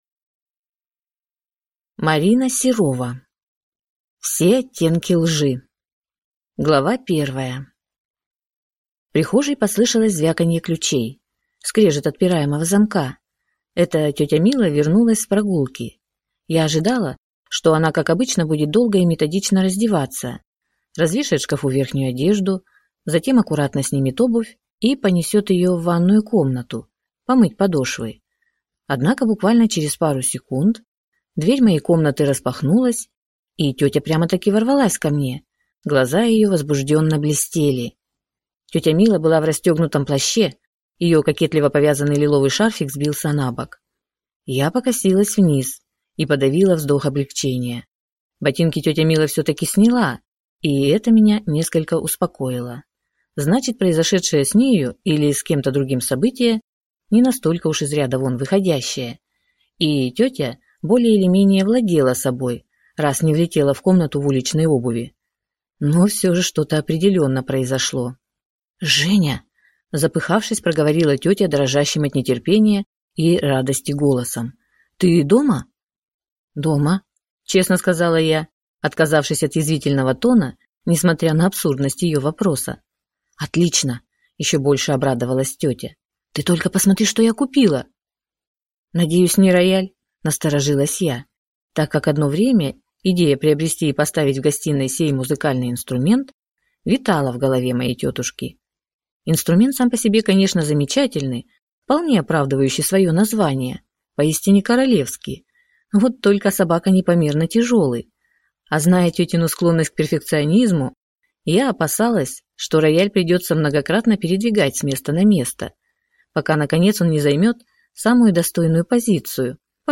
Аудиокнига Все оттенки лжи | Библиотека аудиокниг